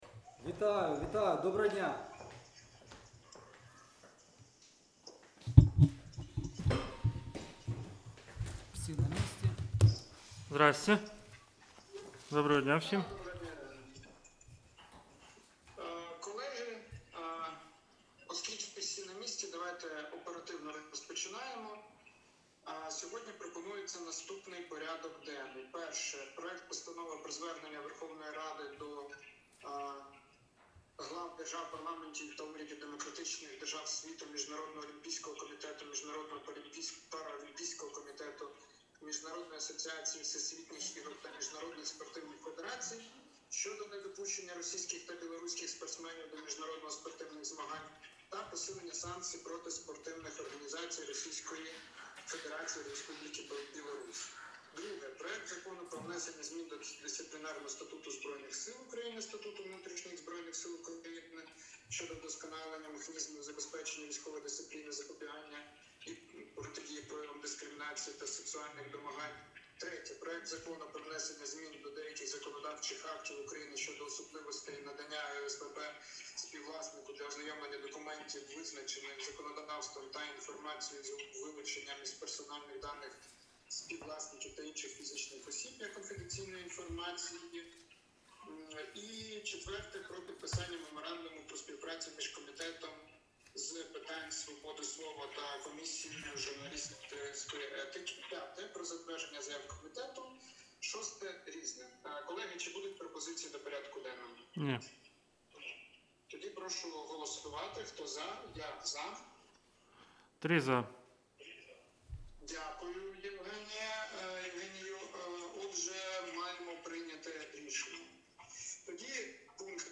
Аудіозапис засідання Комітету від 12 березня 2025р.